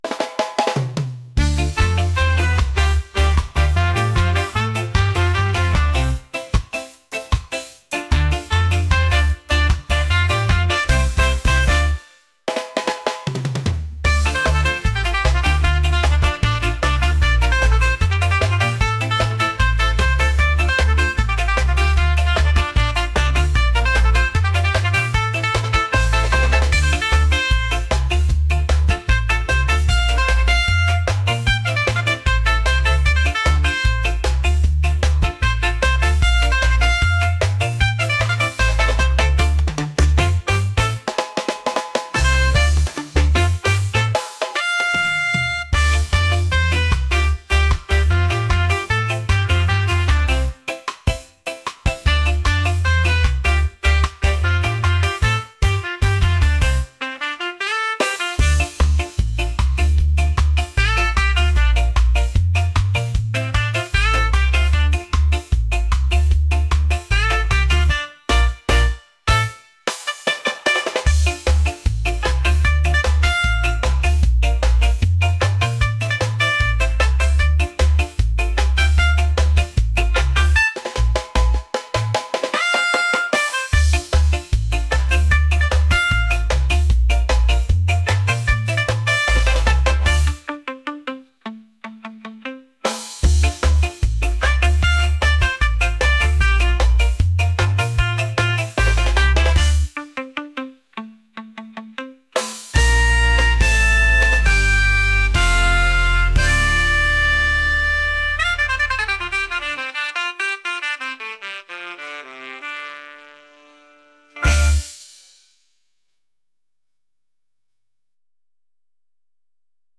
upbeat | energetic | reggae